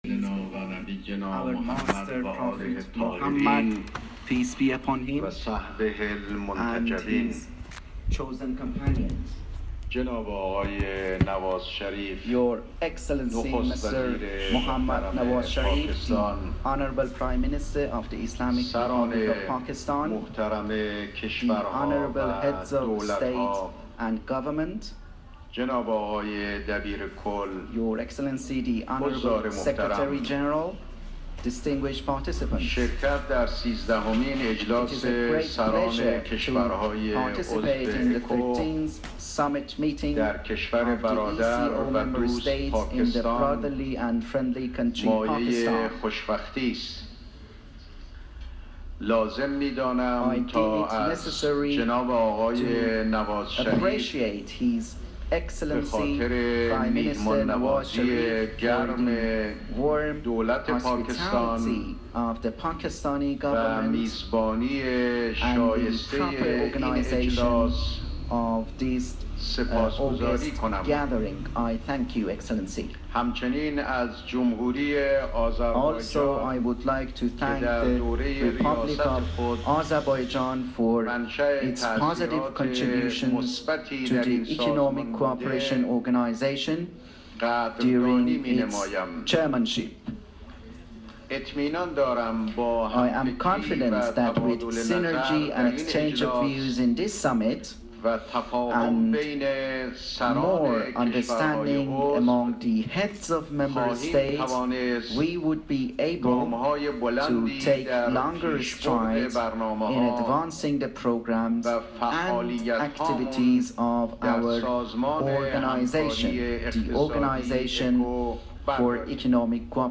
رئیس جمهوری اسلامی ایران در سیزدهمین اجلاس سران اکو اعلام کرد که قلب اقتصاد جهان بزودی در قاره آسیا خواهد تپید و برجام نیز تغییرات مهمی برای ادامه همکاری‌های ایران و جهان ایجاد کرده است.